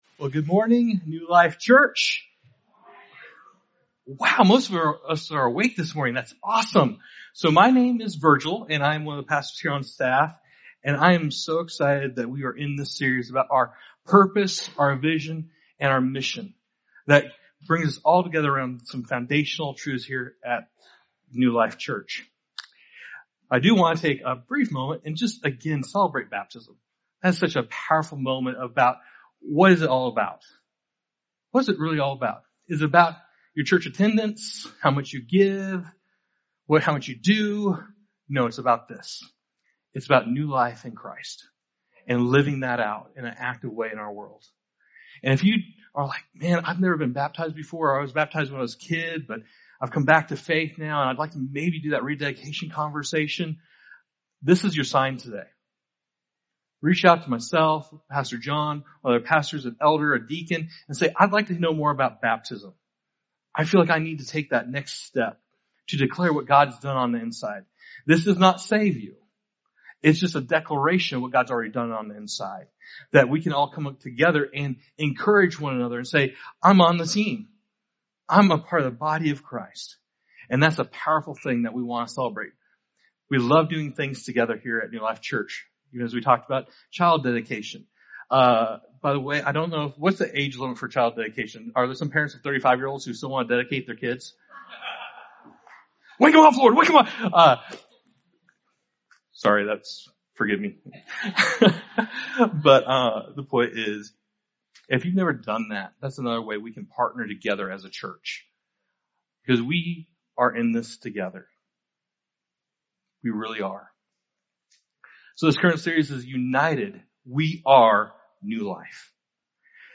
This sermon, "United: We Are New Life," invites us to embrace the heart of Jesus' message from Matthew 22:36-40. We are reminded that loving God with our whole being must come first, and from that place, we are empowered to love those around us.